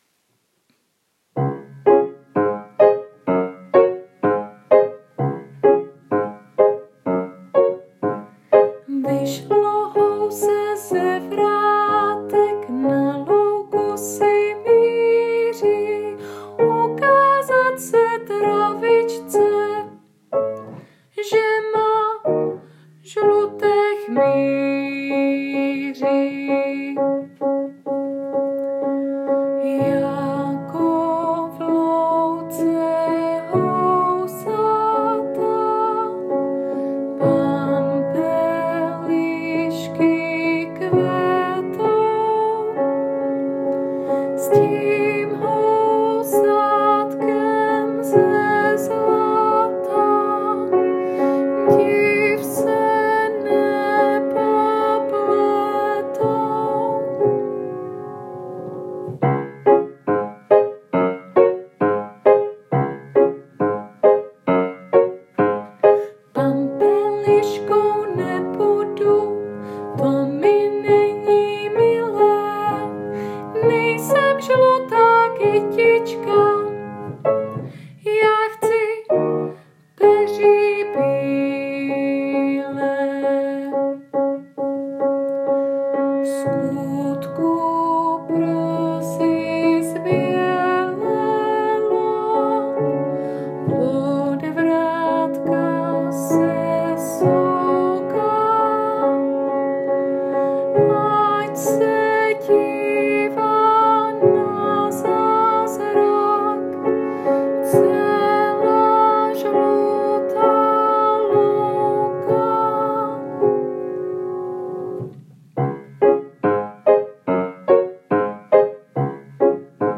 Klikněte ZDE - PAMPELIŠKY / ZPĚV